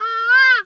bird_peacock_squawk_soft_04.wav